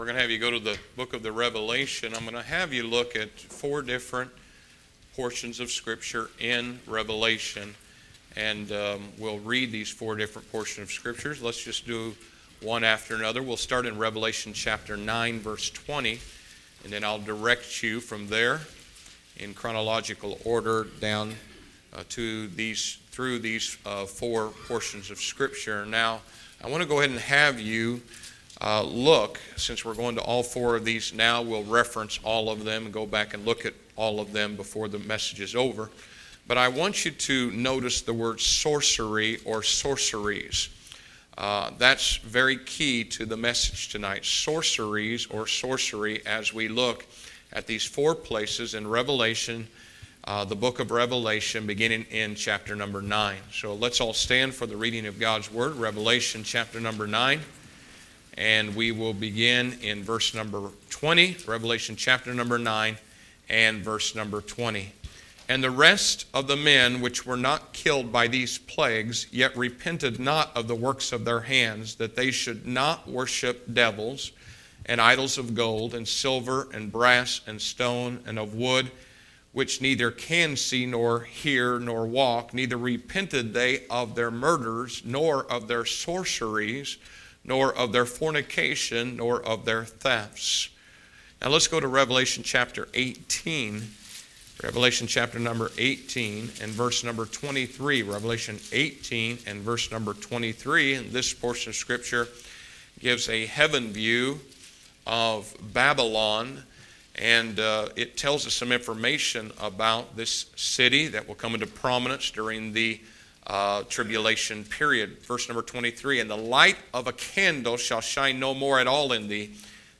2013 SERMONS